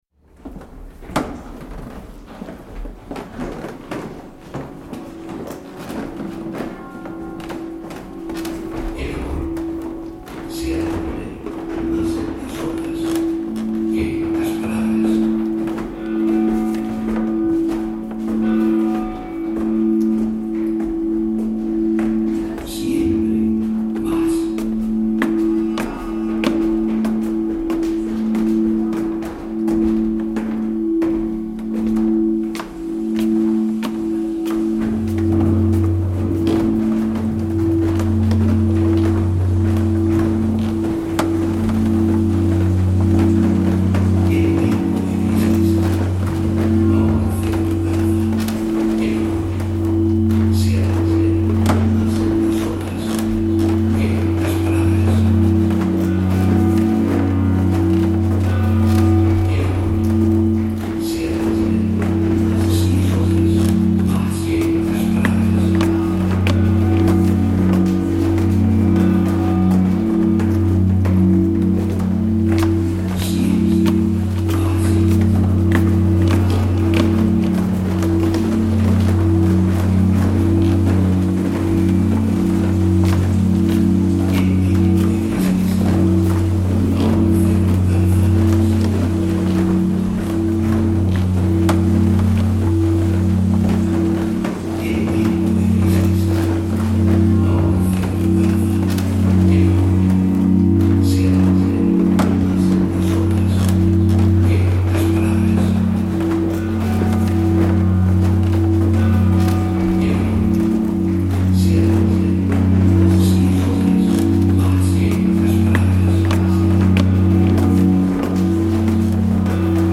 It’s incredibly rich and mysterious.